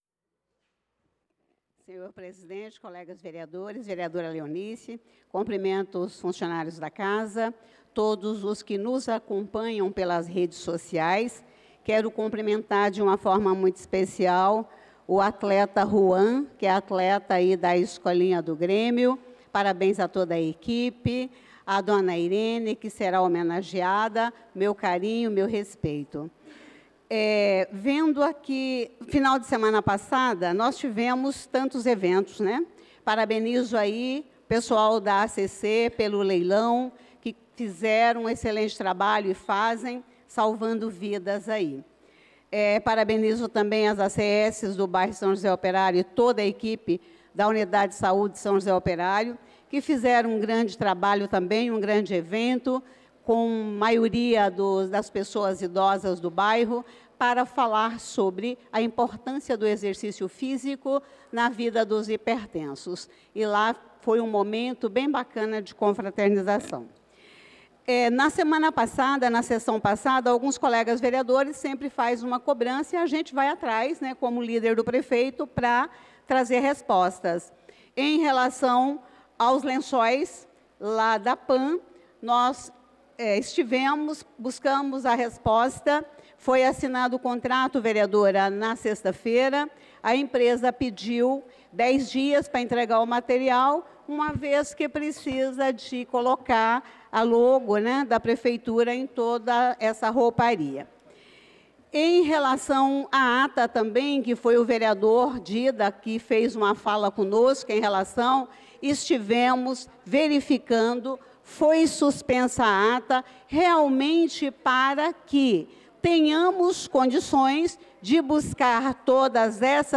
Pronunciamento da vereadora Elisa Gomes na Sessão Ordinária do dia 28/04/2025